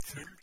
Ääntäminen
IPA : /drʌŋk/